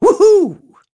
Crow-Vox_Happy5.wav